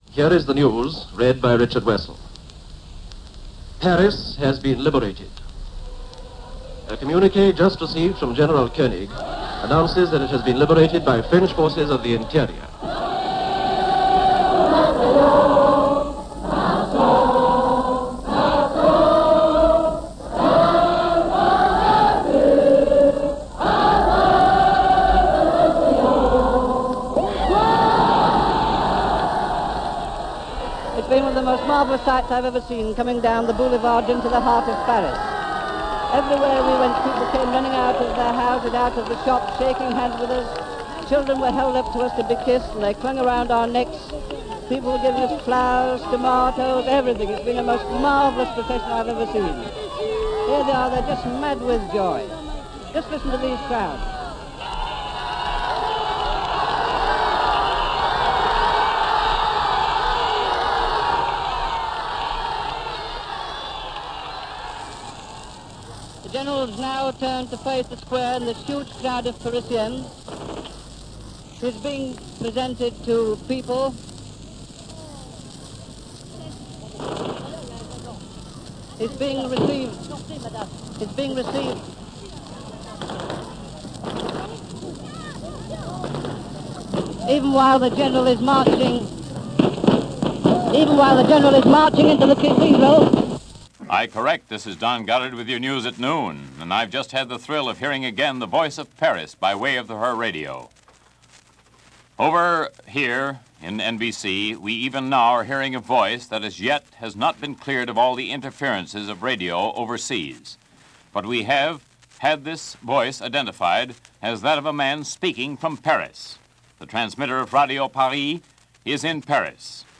Paris Is Liberated - August 25, 1944 - News from The BBC, NBC and a re-creation/documentary on the liberation of Paris.
– August 25, 1944 – News Reports from BBC – NBC and a Radio Documentary from Paris Radio – Gordon Skene Sound Collection –